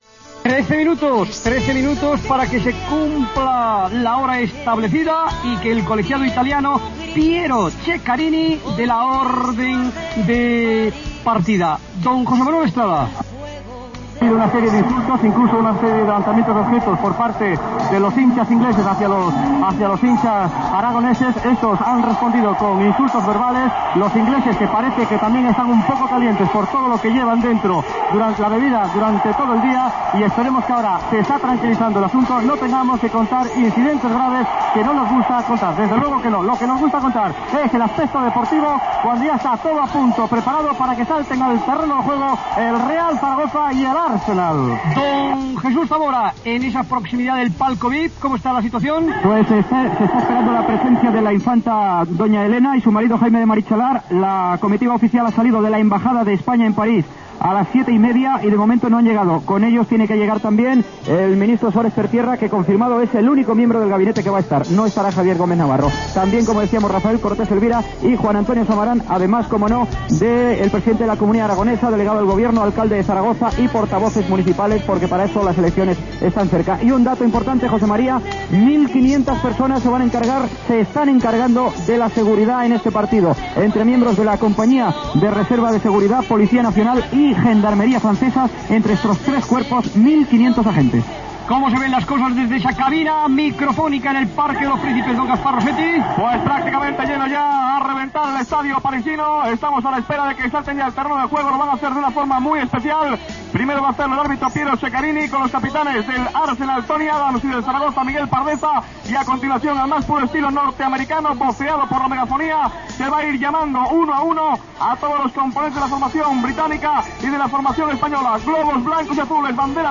Así se narró la final de la Recopa a través de la cadena COPE